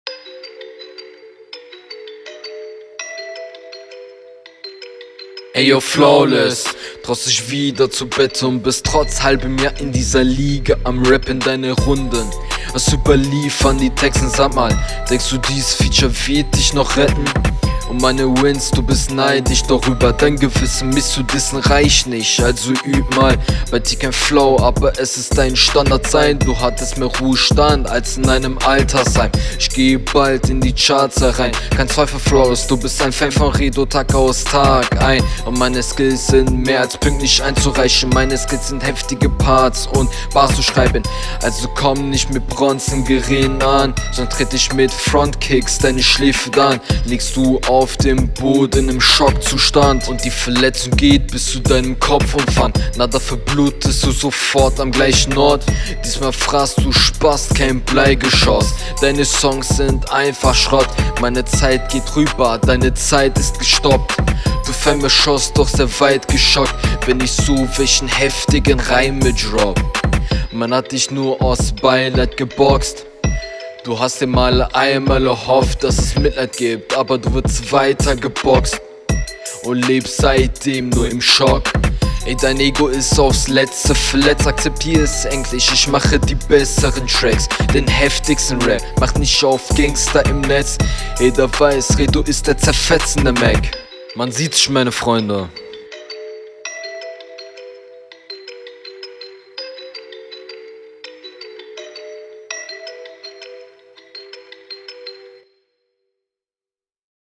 Rappst mindestens so whack wie ich, nur dass fast nichts puncht und die Mische genauso …